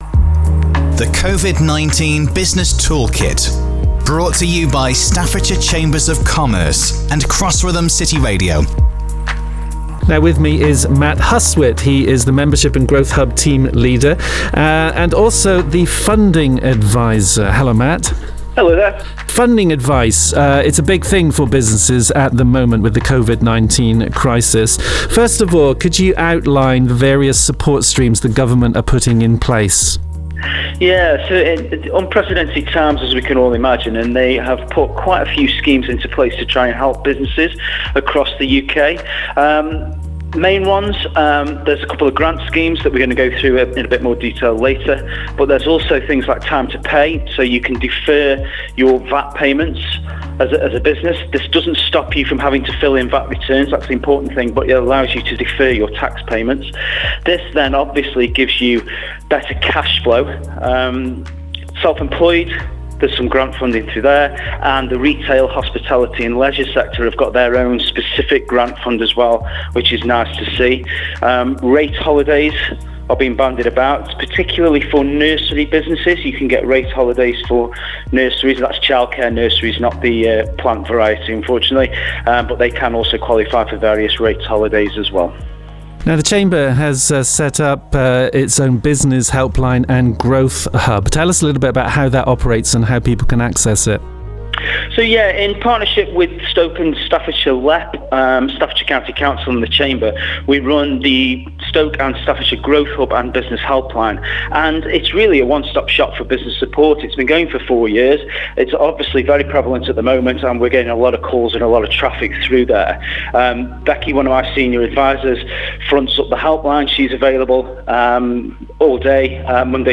Each podcast features interviews with specialists from Staffordshire Chambers of Commerce and covers topics that businesses may find informative, such as: Funding advicejob retention & self employed schemesonline mentoringbusiness crime support and more...